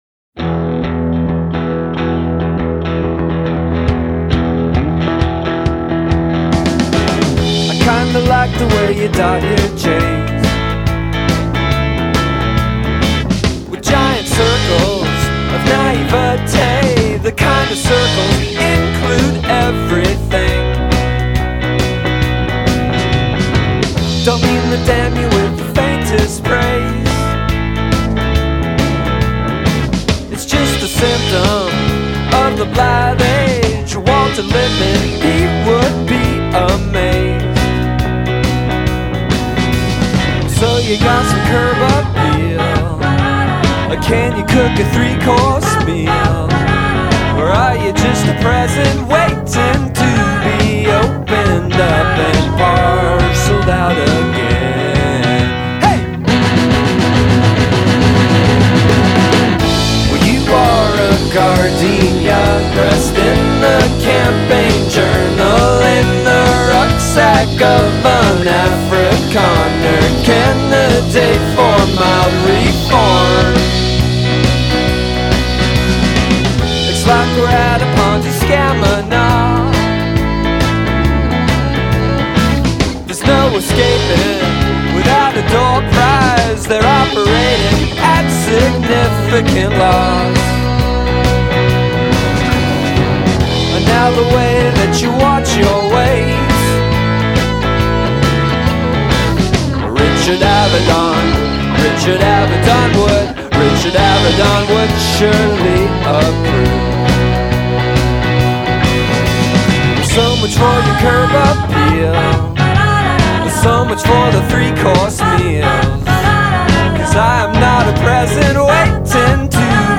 dal ritornello un bel po' ingarbugliato